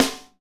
Index of /90_sSampleCDs/Northstar - Drumscapes Roland/SNR_Snares 1/SNR_H_H Snares x